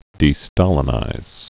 (dē-stälĭ-nĭz)